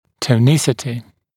[tə(u)ˈnɪsɪtɪ][то(у)ˈнисити]тонус